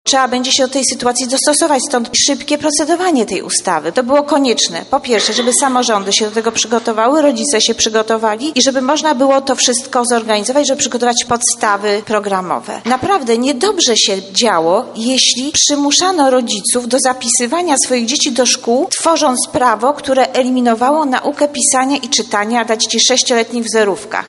Zmiany oczywiście będą generowały koszty, dodaje Machałek